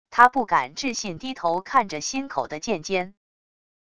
他不敢置信低头看着心口的剑尖wav音频生成系统WAV Audio Player